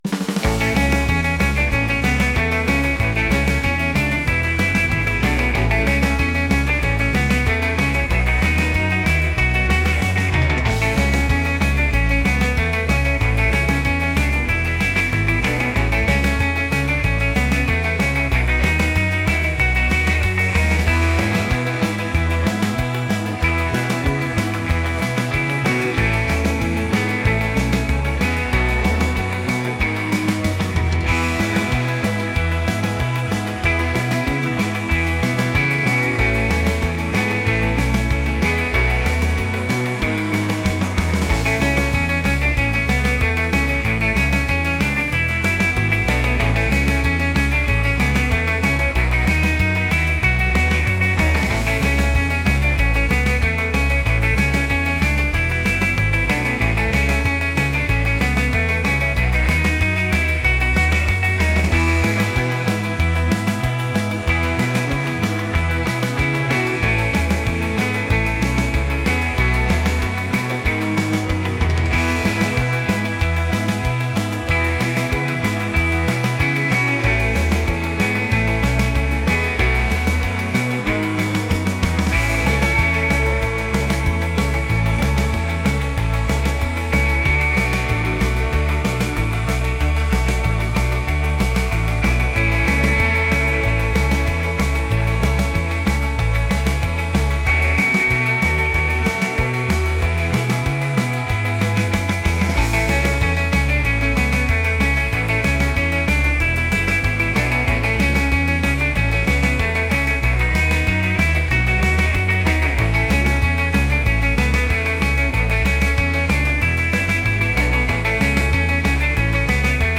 energetic | upbeat | rock